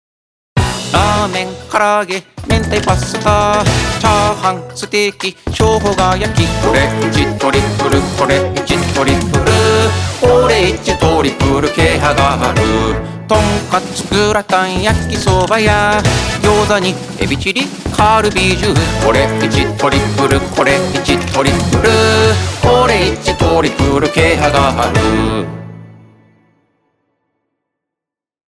ELECTRO
低いトーンだけど、しっかりと耳に刻まれる歌声です。